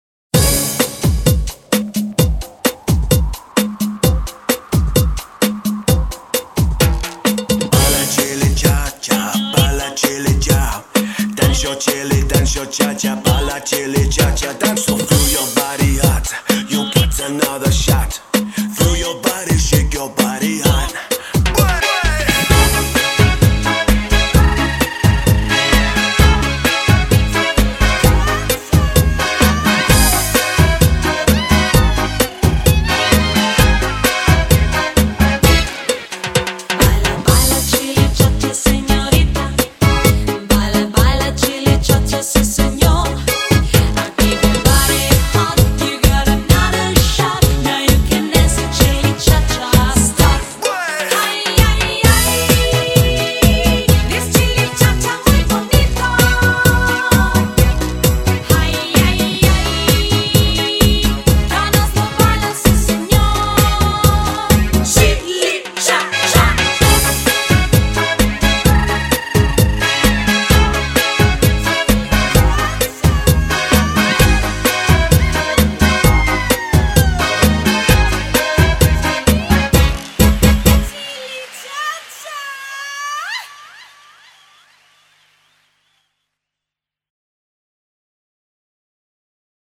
BPM130--1
Audio QualityPerfect (High Quality)